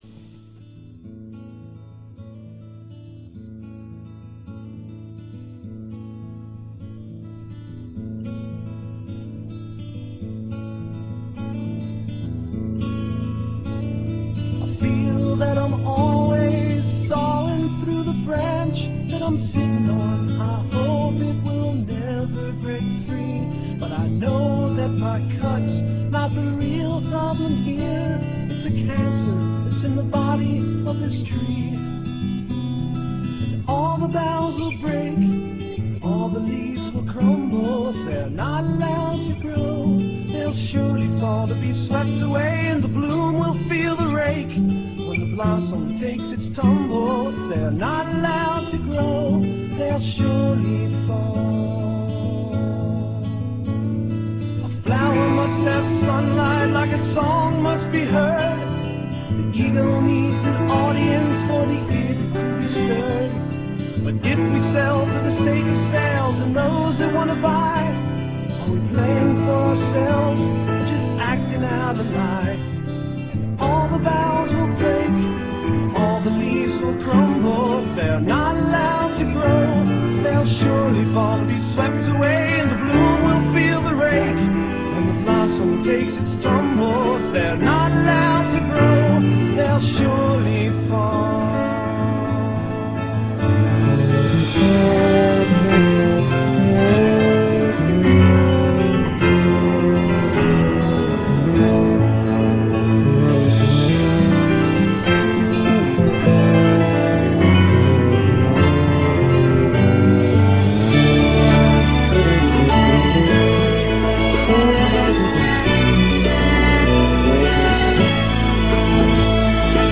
Biblioteca Sonora de Rock Progressivo